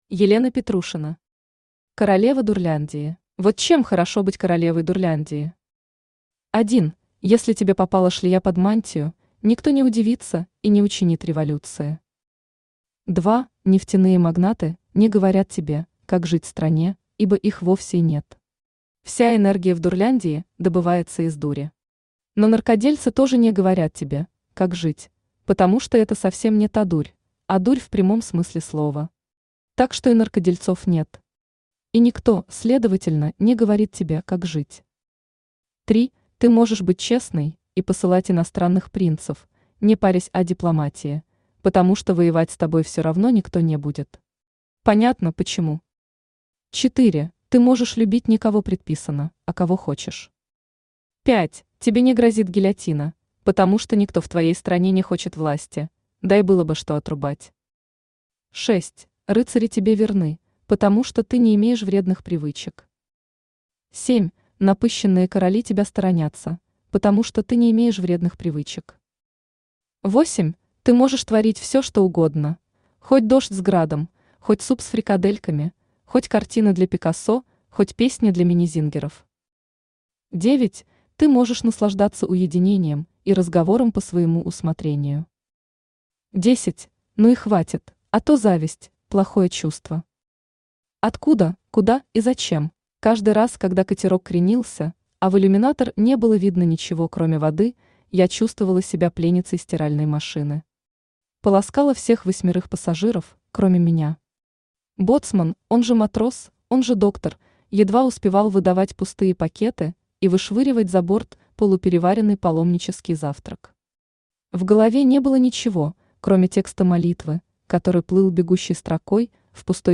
Аудиокнига Королева Дурляндии | Библиотека аудиокниг
Aудиокнига Королева Дурляндии Автор Елена Петрушина Читает аудиокнигу Авточтец ЛитРес.